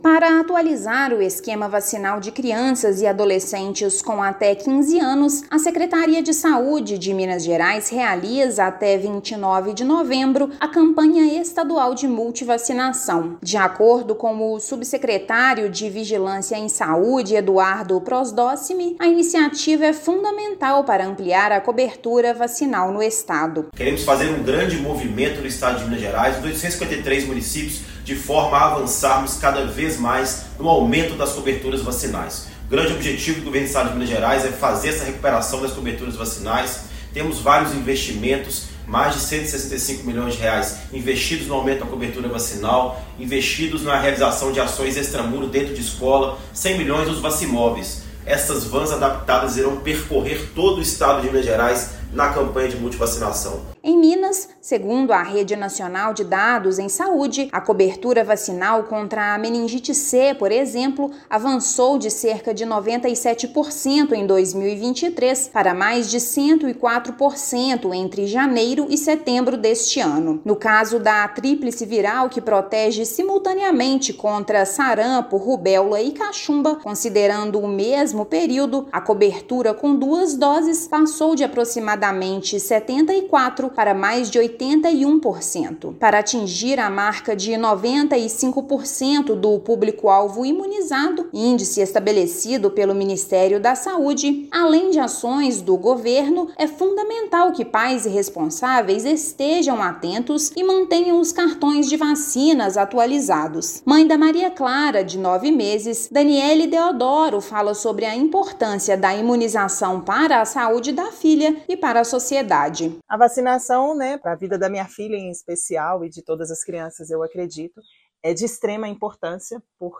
[RÁDIO] Minas Gerais promove campanha de multivacinação para atualizar cartão de vacinas de crianças e adolescentes
Mais de 1,9 milhão de doses foram distribuídas aos municípios para imunizar os mineiros de até 15 anos. Ouça matéria de rádio.